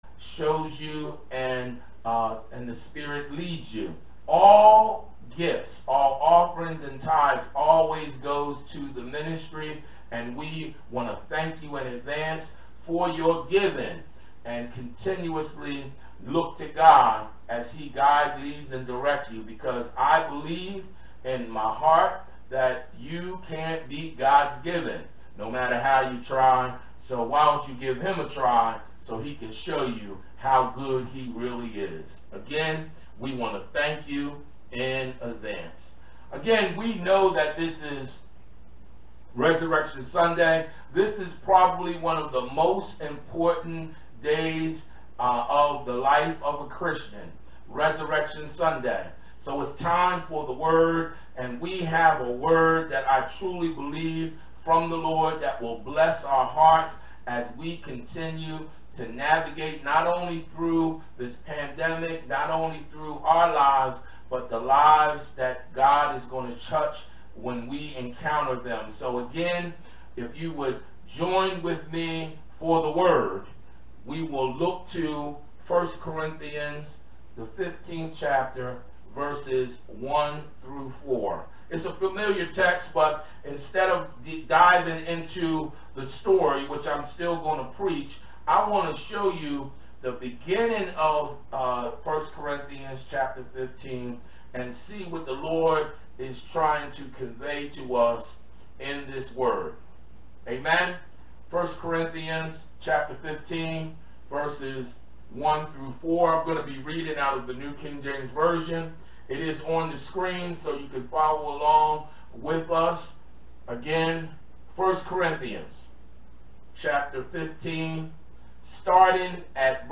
Sermons | Macedonia Baptist Church